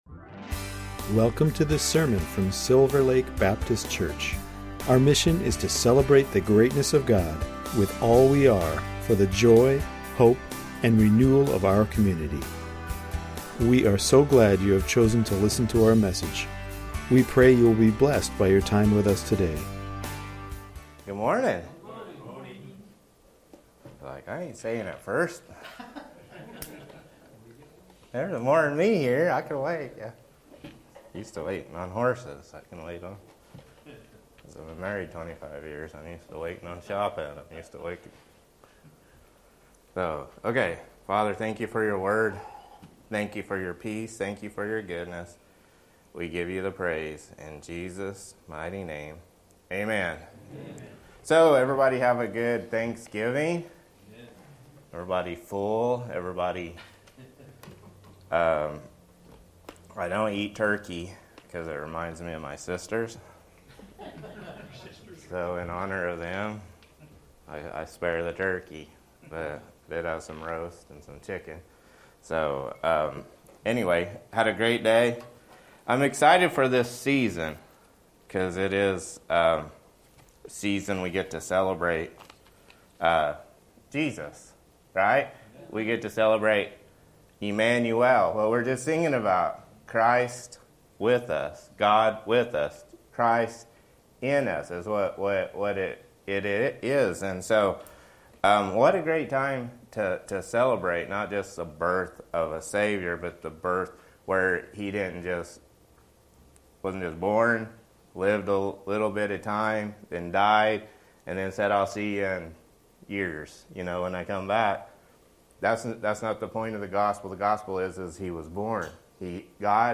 Recent sermons from Silver Lake Baptist Church, Everett, WA